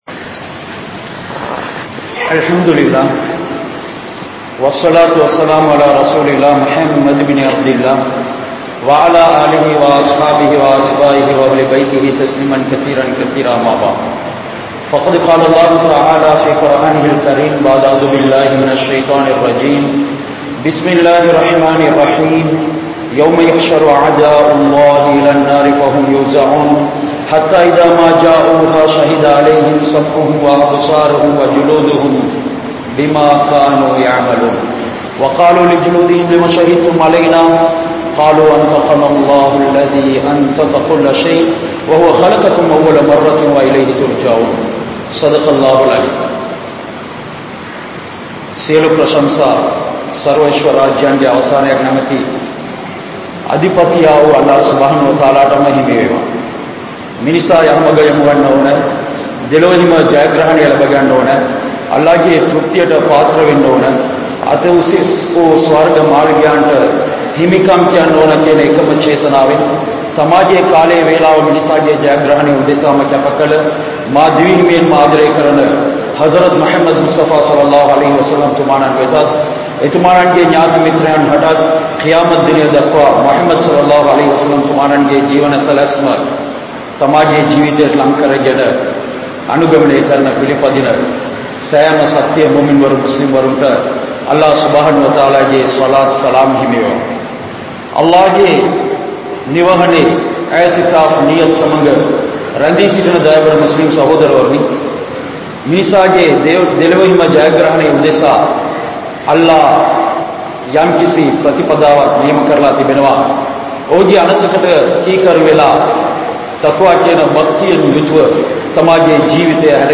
Allah`vitku Kattupadungal (அல்லாஹ்விற்கு கட்டுப்படுங்கள்) | Audio Bayans | All Ceylon Muslim Youth Community | Addalaichenai
Akbar Town Jumua Masjidh